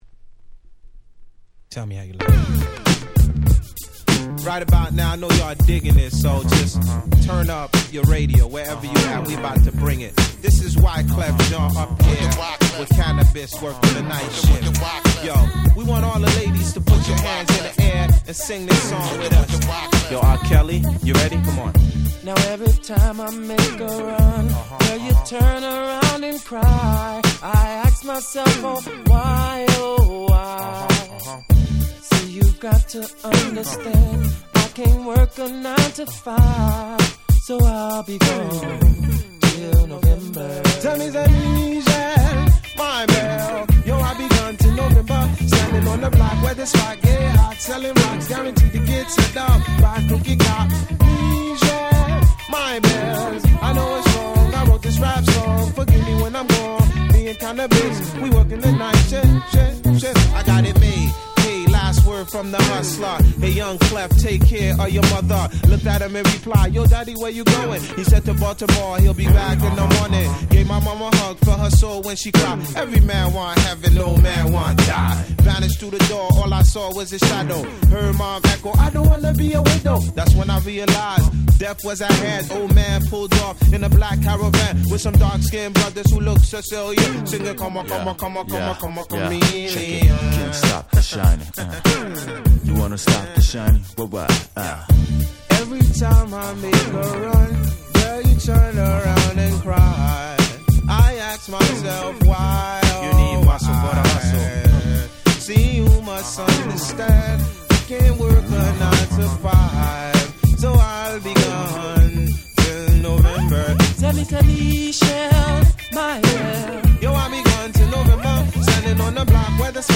97' Super Hit R&B !!